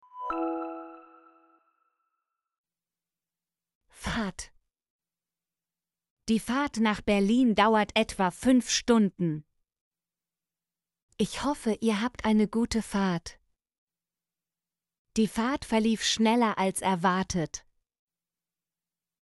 fahrt - Example Sentences & Pronunciation, German Frequency List